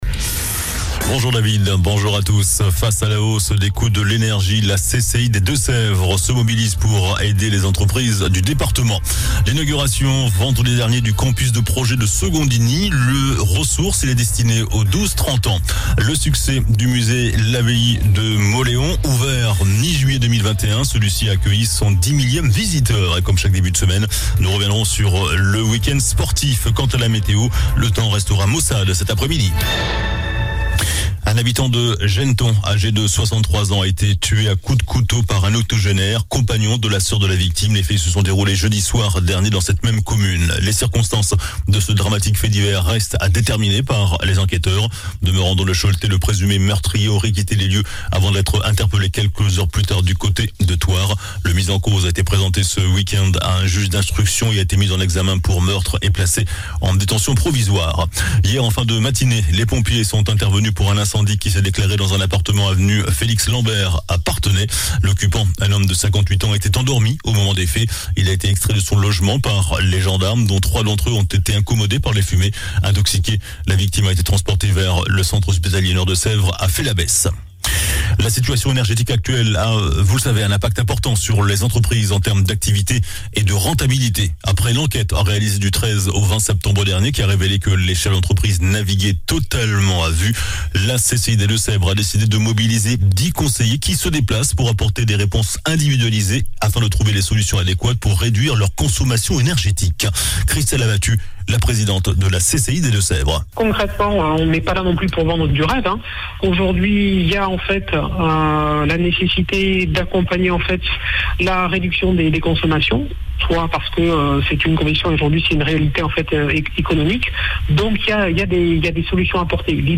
JOURNAL DU LUNDI 17 OCTOBRE ( MIDI )